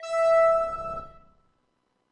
描述：通过Behringer调音台采样到MPC 1000。它听起来一点也不像风的部分，因此被称为Broken Wind。
Tag: 模拟 多样品 合成器 虚拟模拟